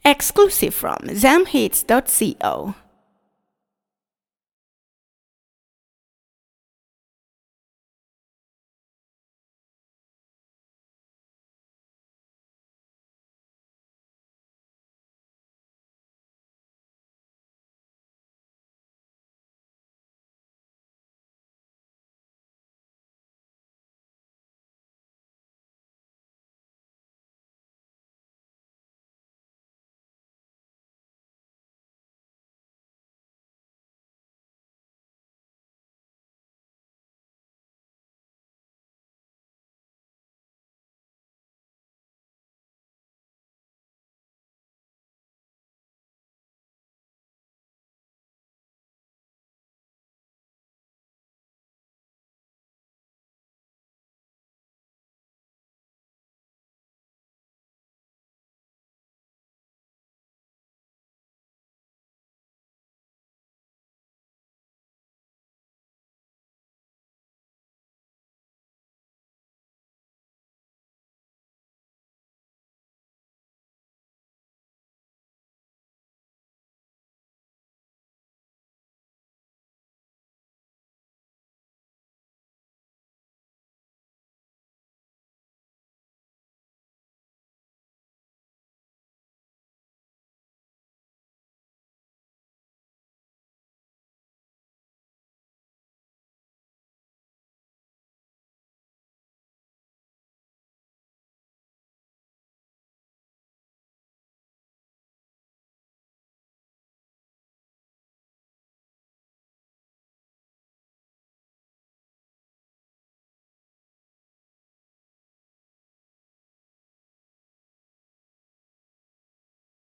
soulful vocals
energetic and conscious verses